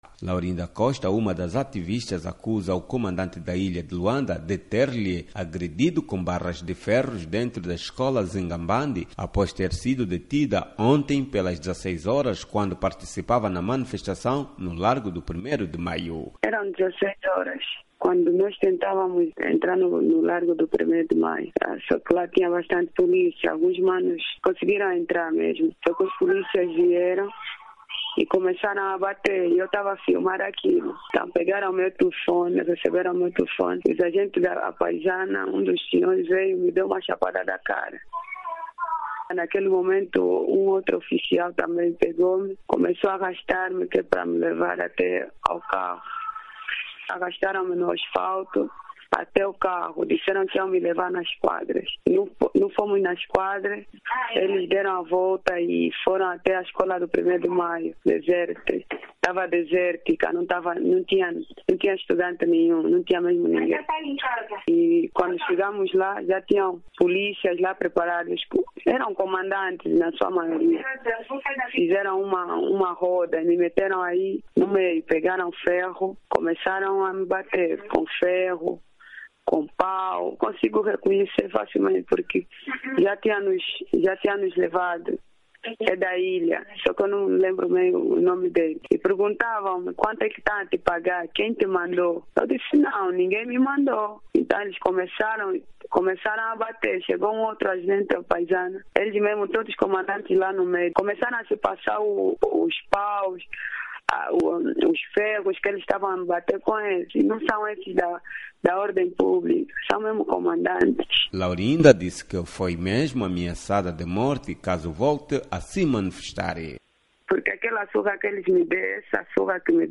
Activista descreve agressoes policiais - 2:40